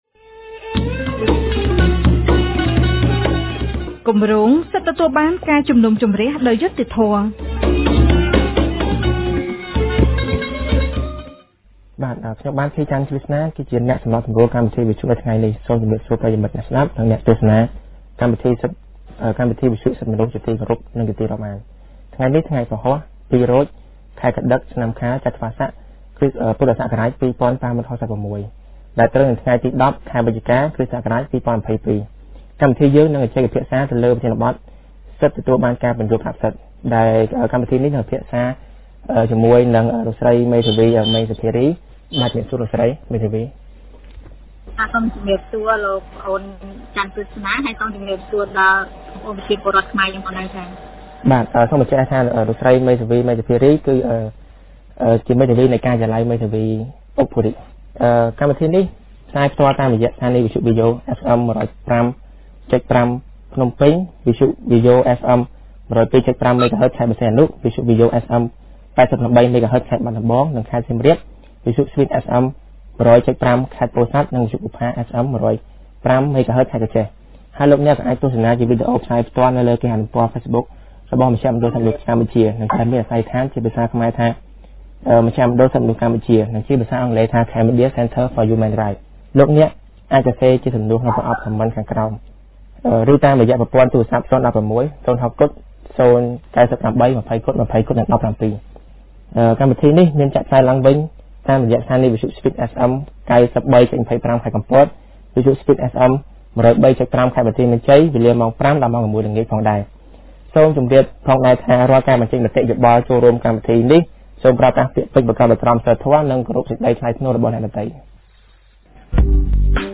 On 10 November 2022, CCHR’s Fair Trial Rights Project (FTRP) held a radio program with a topic on right to explanation of right for accused.